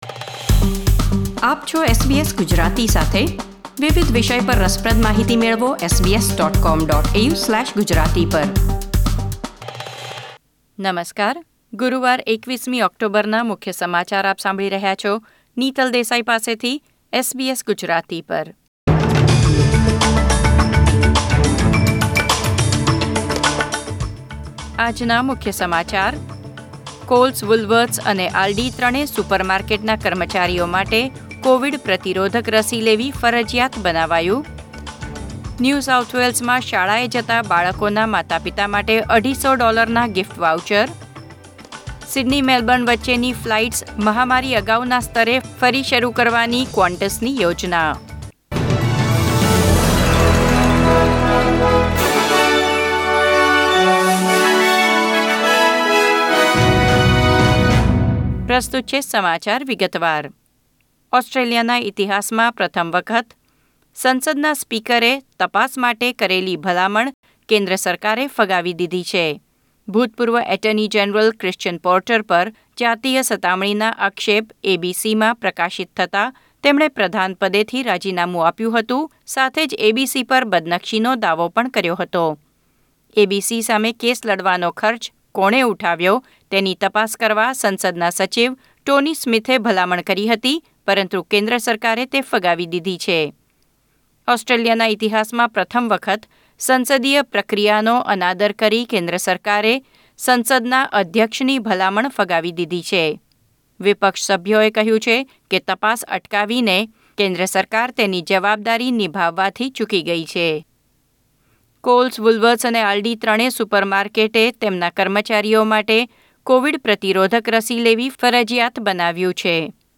SBS Gujarati News Bulletin 21 October 2021